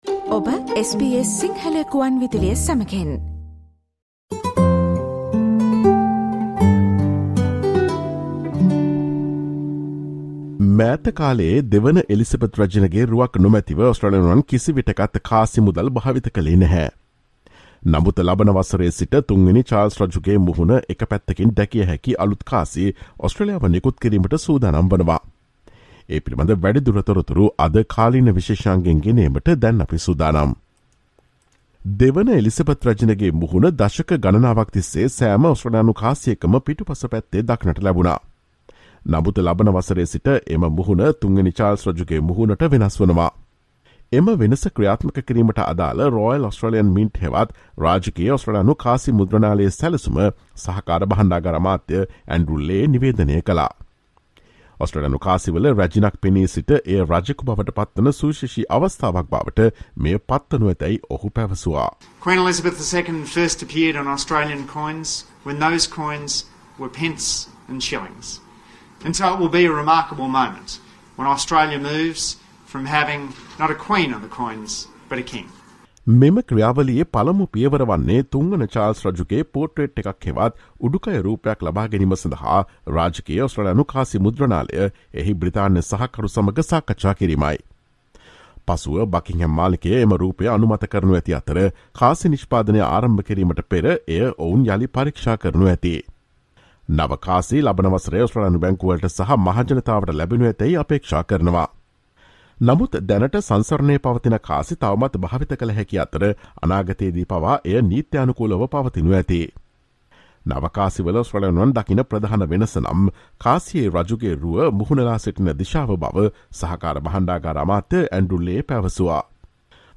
From next year, new Australian coins will see the face of King Charles III on one side. Listen to the SBS Sinhala Radio's current affairs feature broadcast on Friday 16 September.